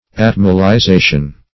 Atmolyzation \At`mol*y*za"tion\, n.